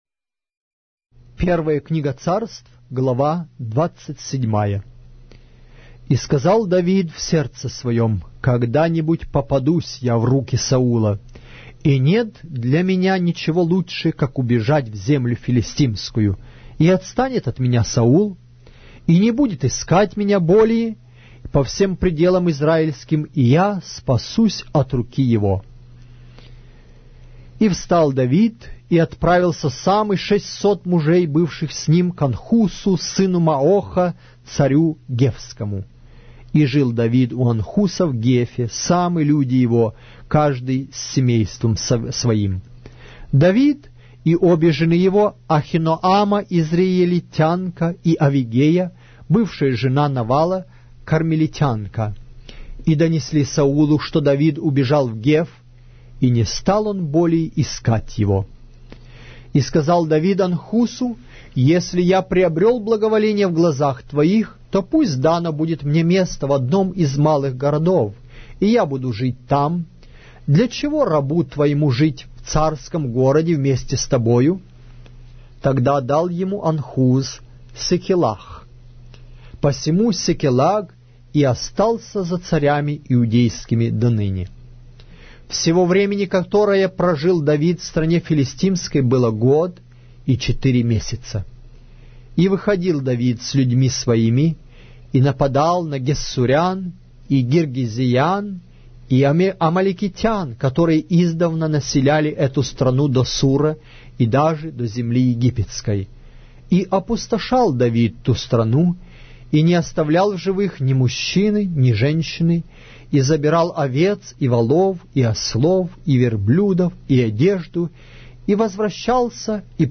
Глава русской Библии с аудио повествования - 1 Samuel, chapter 27 of the Holy Bible in Russian language